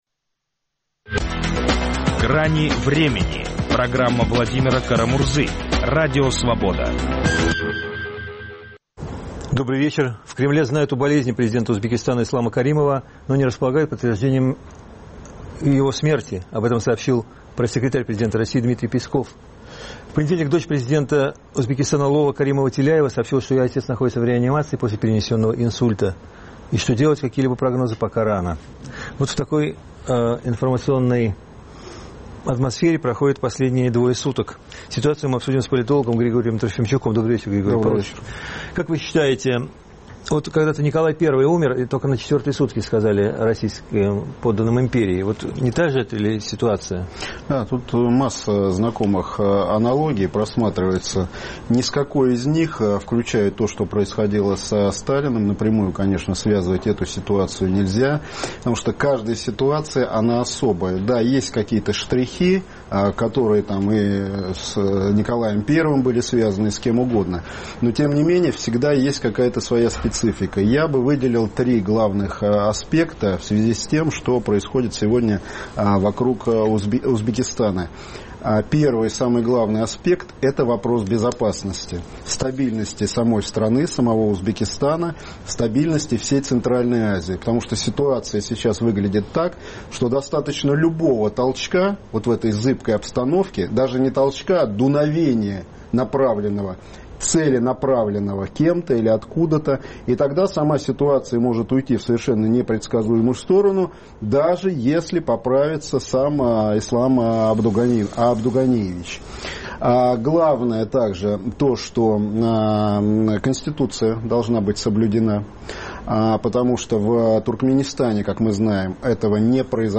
Нет Каримова – нет Узбекистана?Ситуацию обсуждают политолог